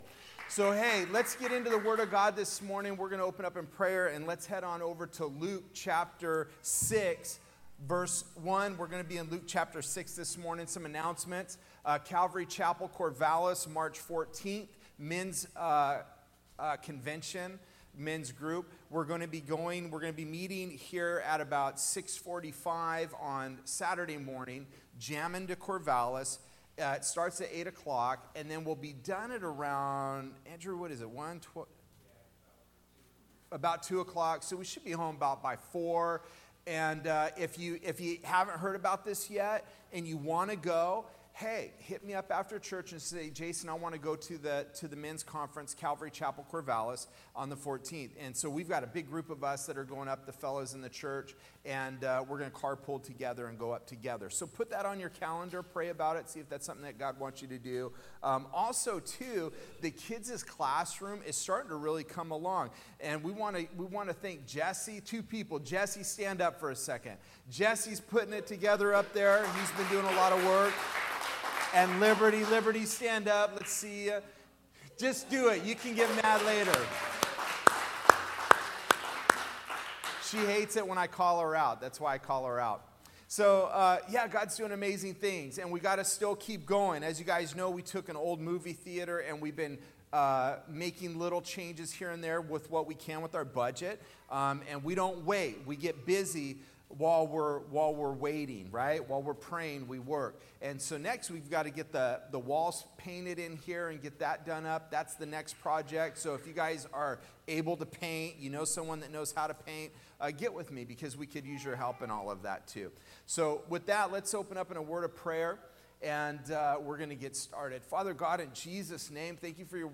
1st Corinthians Bible study Chapter 6